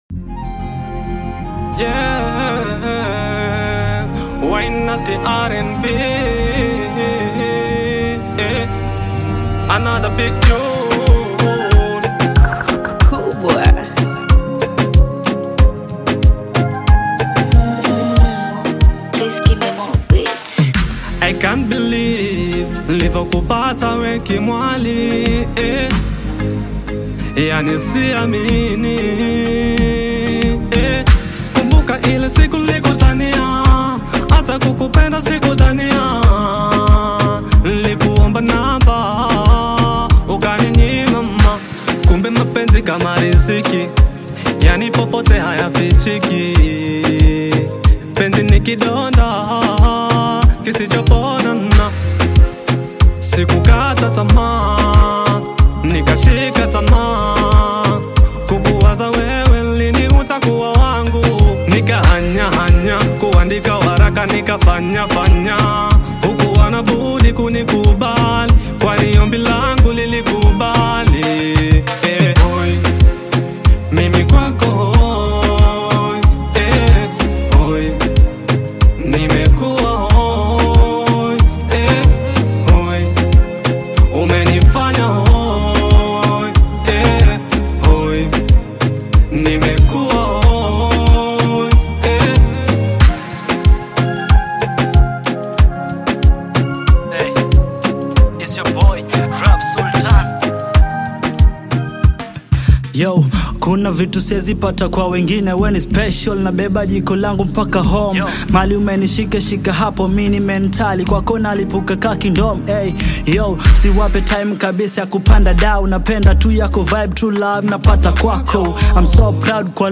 RnB Singer